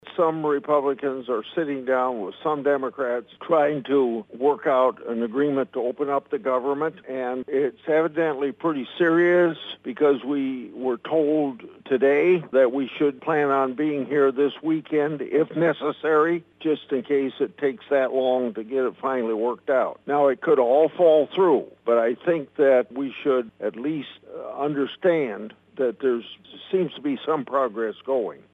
Grassley made his comments this (Monday) afternoon during his weekly Capitol Hill Report with Iowa reporters.